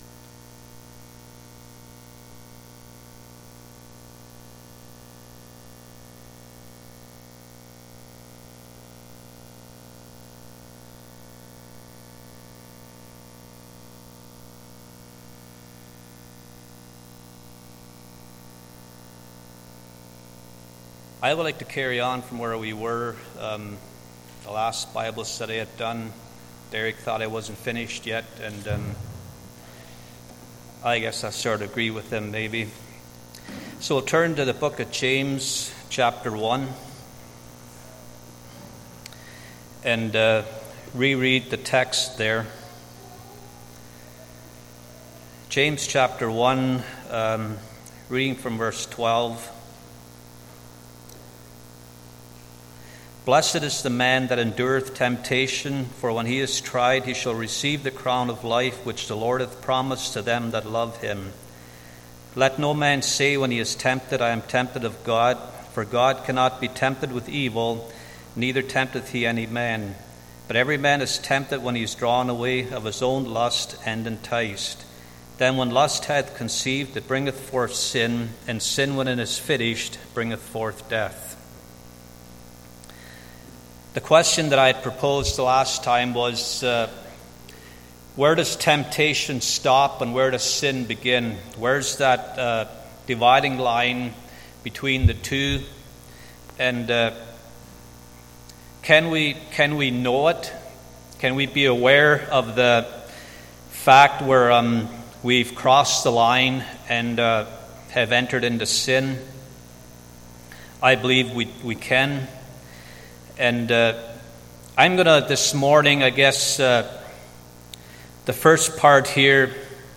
Sunday Morning Bible Study Service Type: Sunday Morning